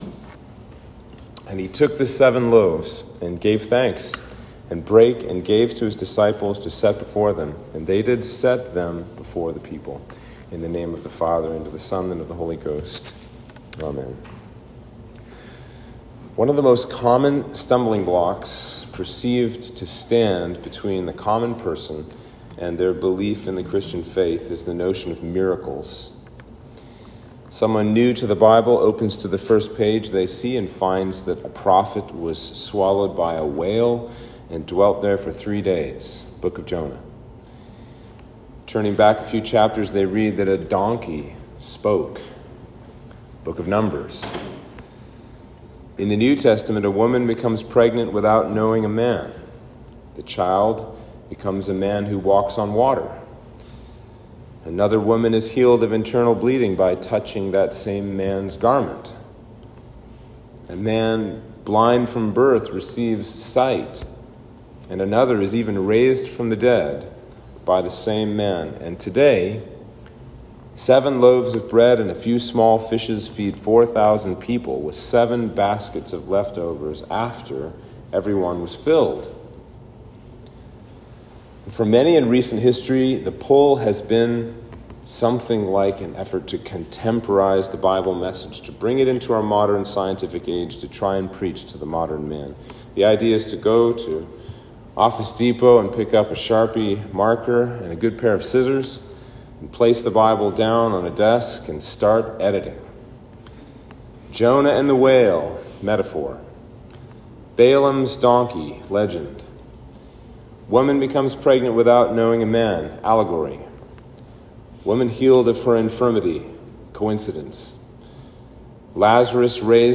Sermon for Trinity 7